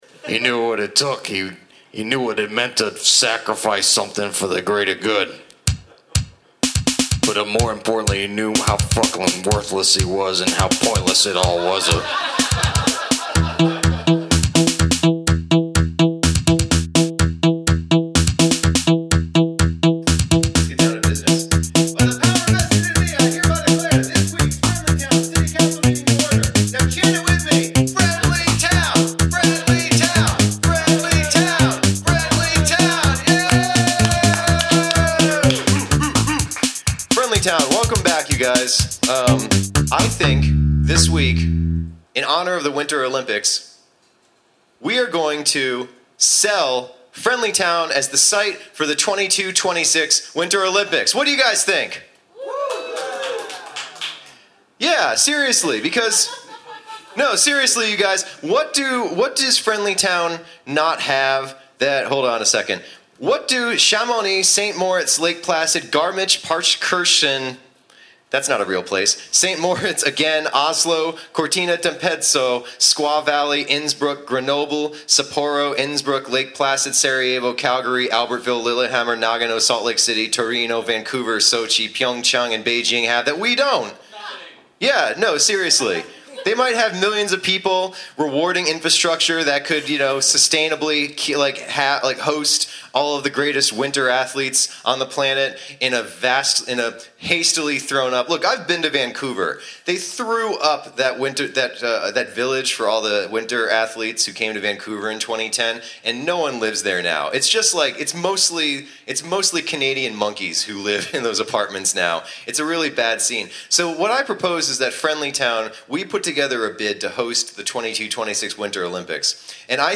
Recorded Live at the Pilot Light February 12, 2017, Knoxville TN Share this: Share on X (Opens in new window) X Share on Facebook (Opens in new window) Facebook Share on Pinterest (Opens in new window) Pinterest Like Loading...